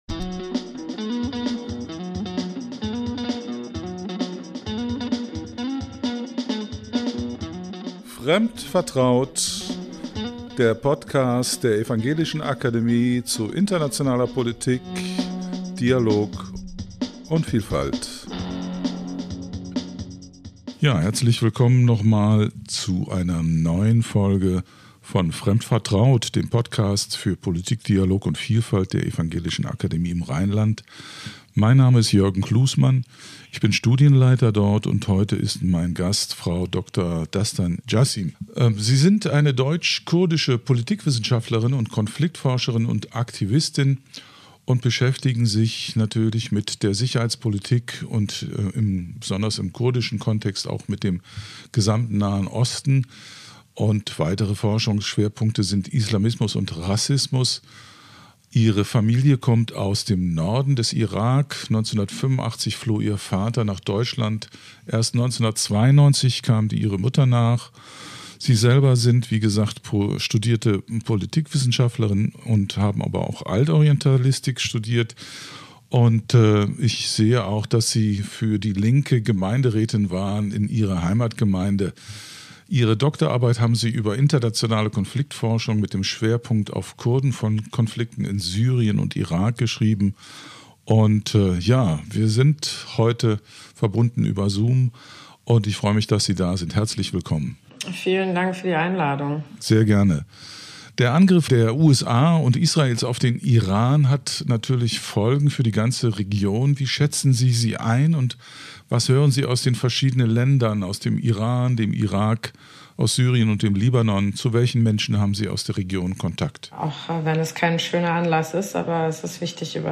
Gespräch mit der Politikwissenschaftlerin